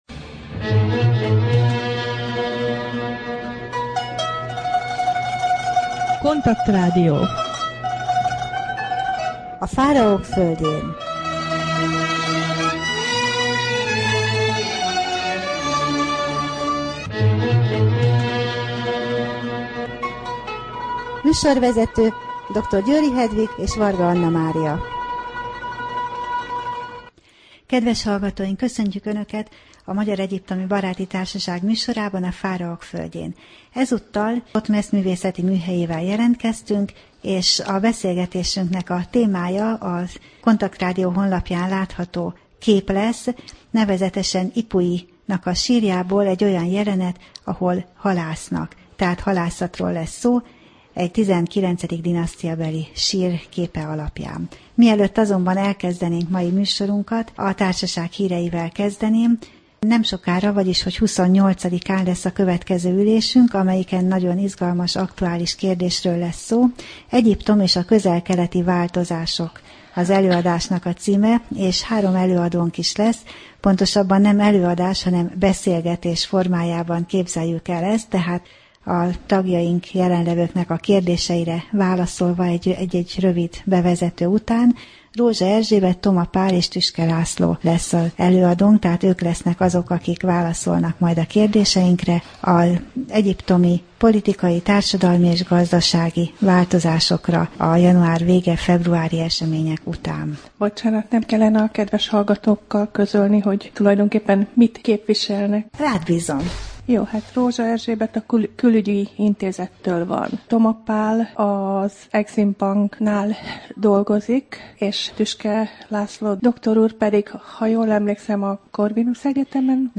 Rádió: Fáraók földjén Adás dátuma: 2011, March 18 Thotmesz művészeti műhelye / KONTAKT Rádió (87,6 MHz) 2011 március 18.